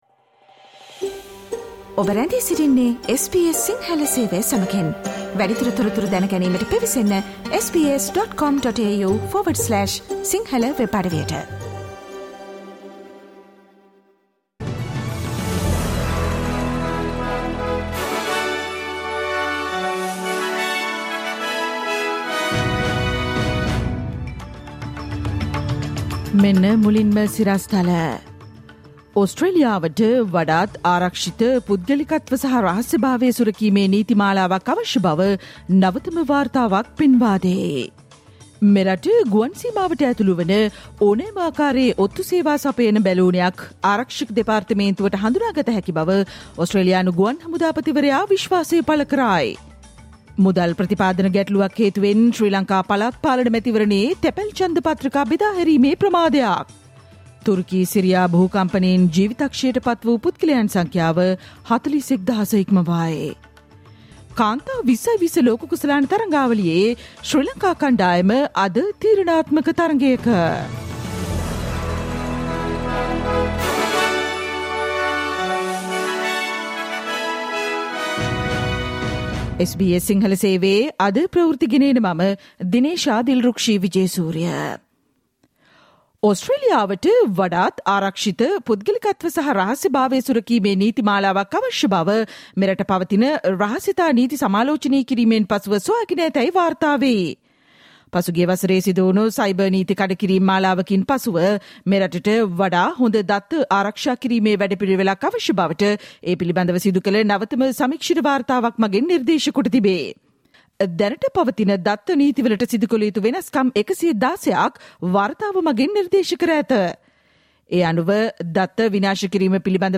සවන්දෙන්න, අද - 2023 පෙබරවාරි 16 වන බ්‍රහස්පතින්දා SBS ගුවන්විදුලියේ ප්‍රවෘත්ති ප්‍රකාශයට